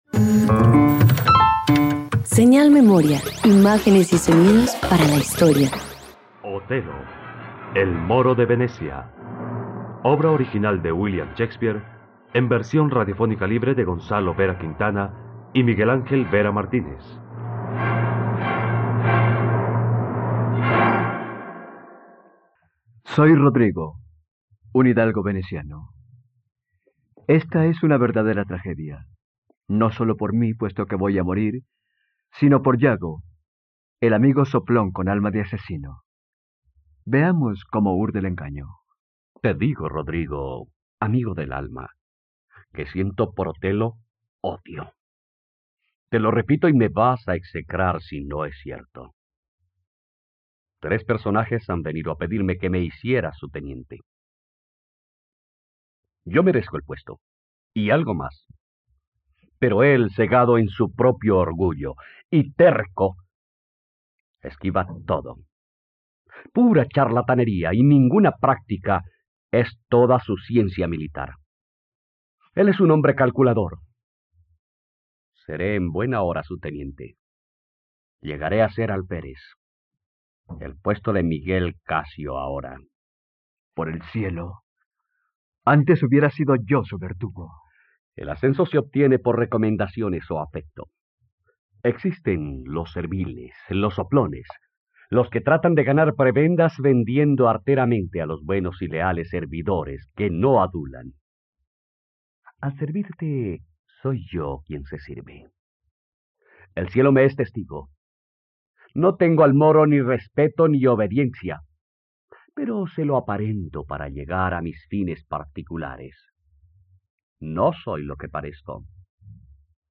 ..Radioteatro. Escucha ahora la historia de Otelo: el moro de Venecia, obra original de William Shakespeare, en la plataforma de streaming RTVCPlay.
La adaptación radiofónica de la obra original del dramaturgo inglés William Shakespeare narra la historia de Otelo, líder de las tropas venecianas en Chipre, que después de creer un comentario malintencionado asesina a su esposa y acaba con su vida.